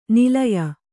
♪ nilaya